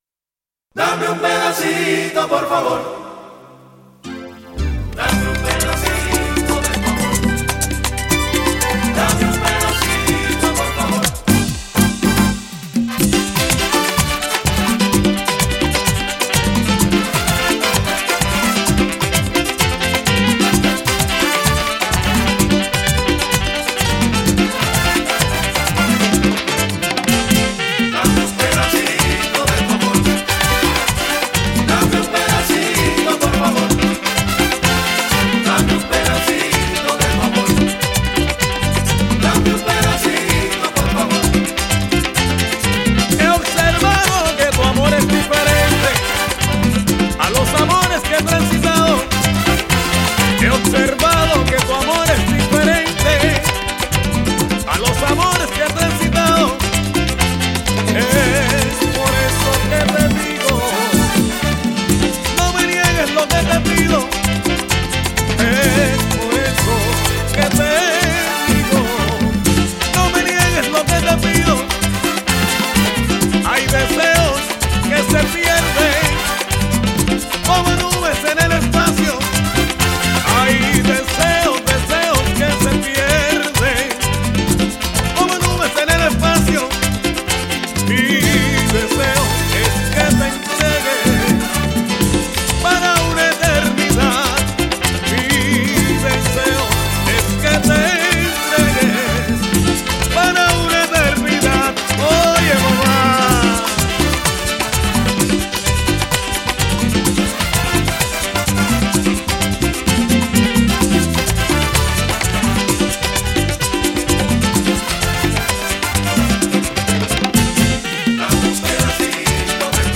Žánr: World music/Ethno/Folk
Súčasná kubánska popová interpretácie salsy